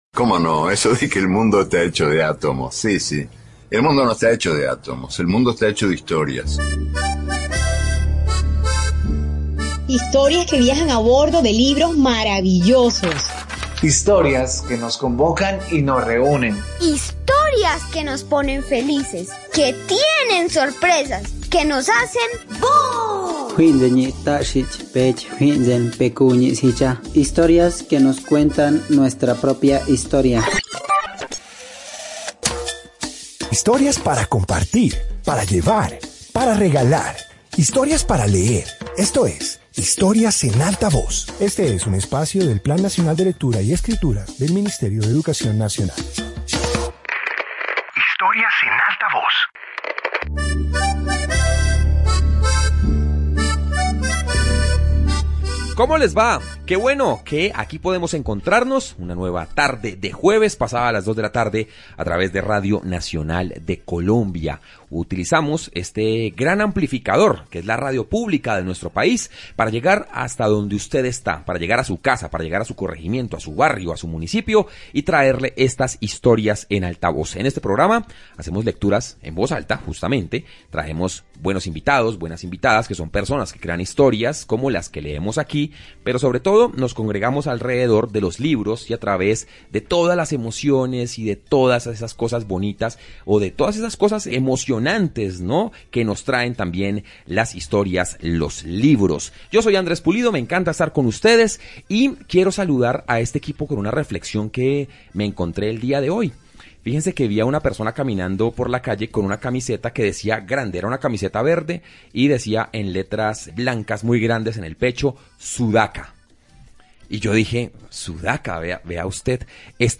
Introducción Este episodio de radio comparte historias de diferentes regiones del continente americano. Presenta narraciones que evocan raíces culturales, tradiciones y elementos que conforman la identidad latinoamericana.